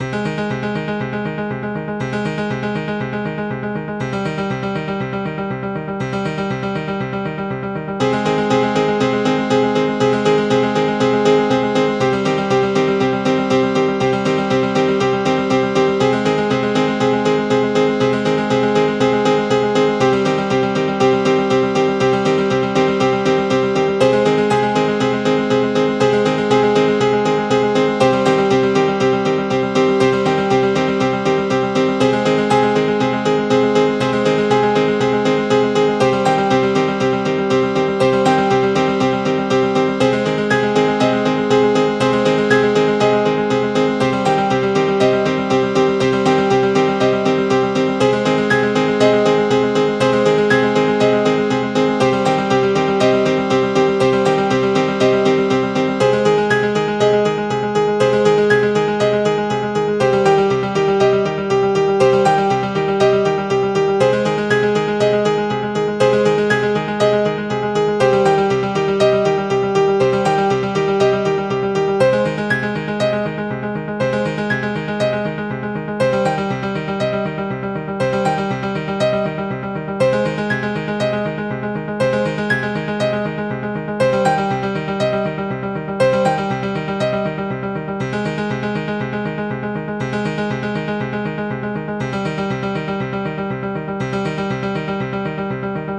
Pieza de piano minimalista
piano
minimalista